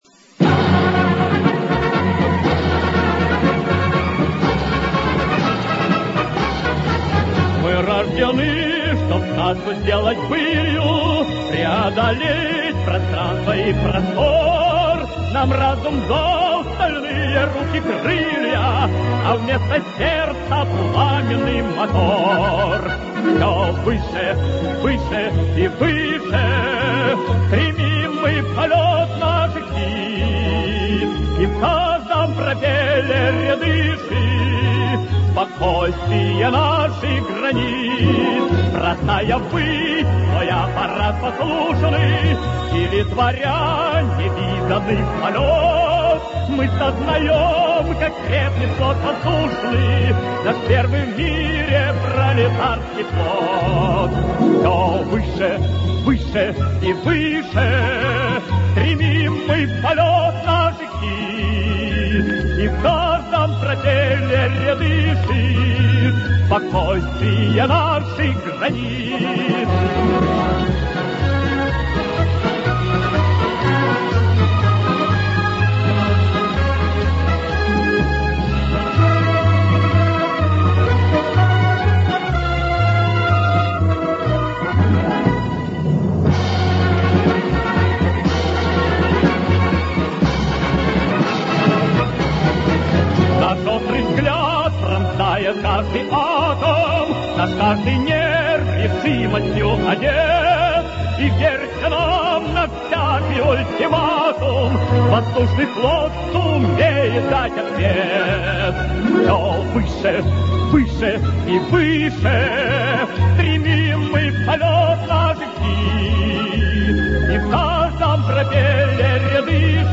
sovetskie_voennye_marshi_i_pesni-aviacionnyj_marsh.mp3